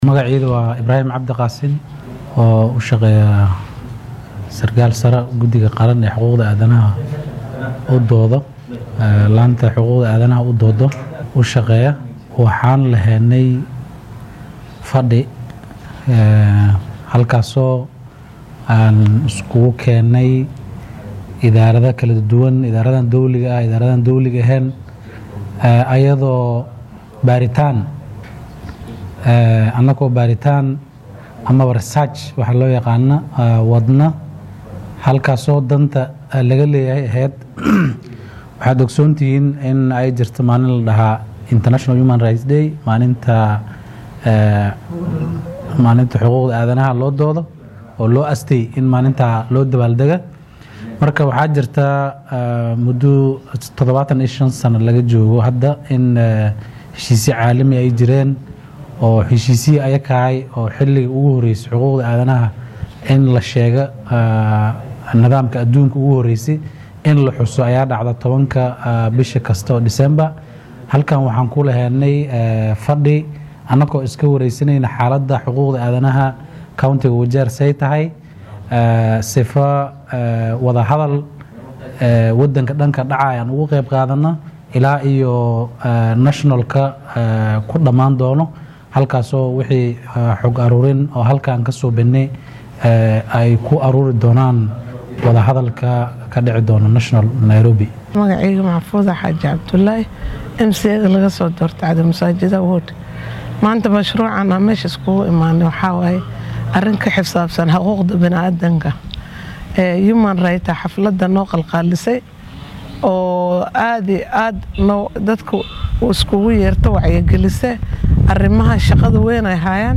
Waxaa magaalada Wajeer lagu qabtay kulan ay ka soo qayb galeen daneeyayaal kala duwan kaasoo looga hadlayay sare u qaadidda wacyiga bulshada ee dhanka xuquuqul aadanaha. Kulanka ayaa sidoo kale lagu eegay ka hor tagga xadgudubyada mararka qaar la soo tabiyo sida dilalka sharci darrada ah ee lagu eedeyo saraakiisha ammaanka. Qaar ka mid ah mas’uuliyiintii shirkaasi soo qaban qaabiyay iyo ka soo qayb galayaasha ayaa waraabinta faahfaahin ka siiyay.